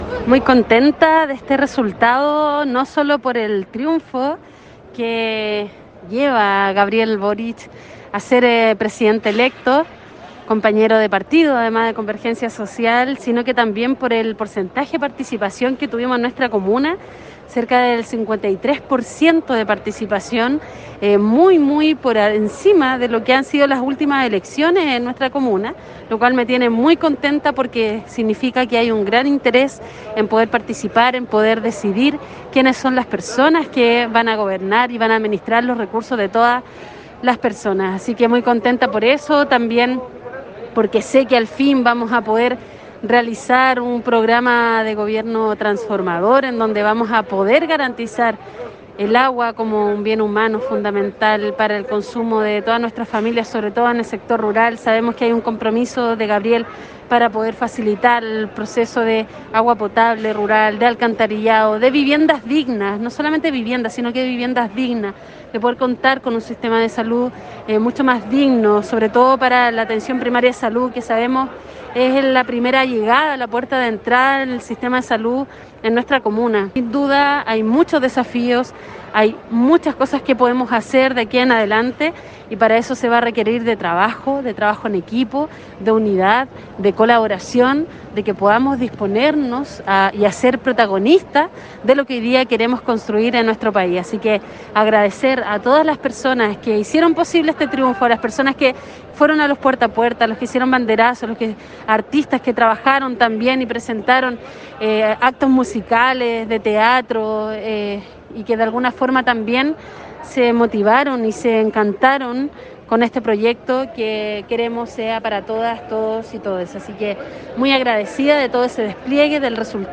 Desde el multitudinario acto que a esta hora se realiza en Santiago con el recibimiento del Presidente Electo, Gabriel Boric, la Alcaldesa de Melipilla, Lorena Olavarría, envía un cordial saludo a todos los votantes melipillanos en esta histórica elección presidencial en Chile.